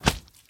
Minecraft Version Minecraft Version 25w18a Latest Release | Latest Snapshot 25w18a / assets / minecraft / sounds / mob / magmacube / big2.ogg Compare With Compare With Latest Release | Latest Snapshot